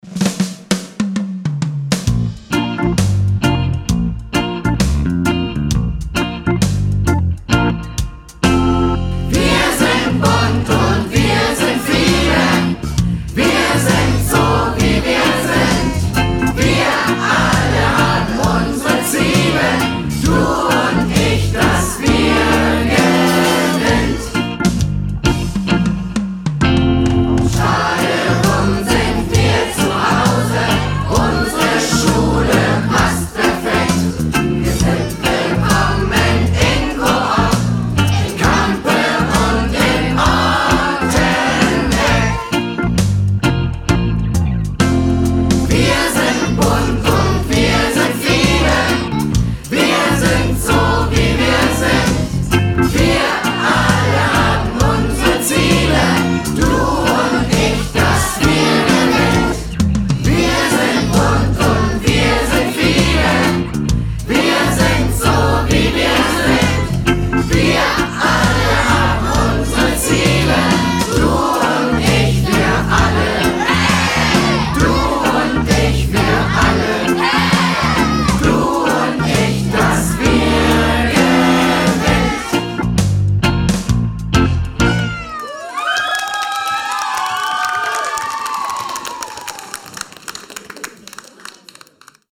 Wir haben eine Schulhymne- Viel Spaß beim Hören und Mitsingen!
schulhymne.mp3